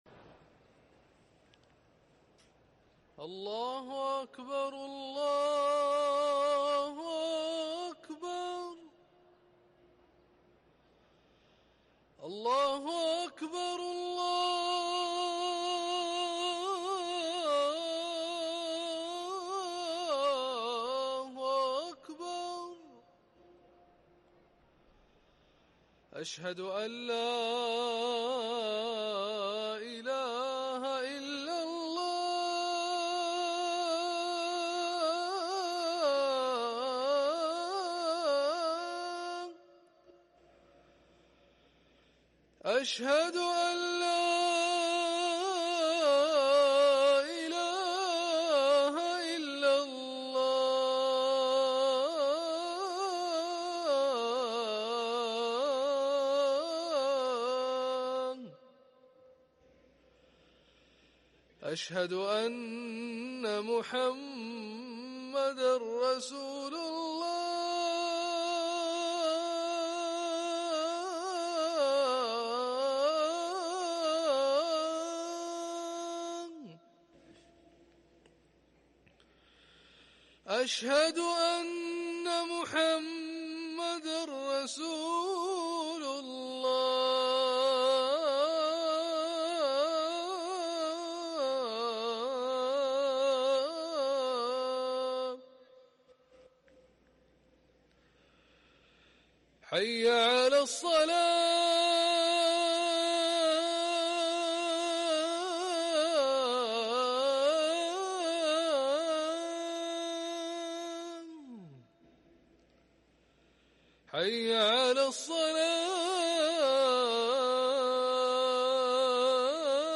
اذان الظهر
ركن الأذان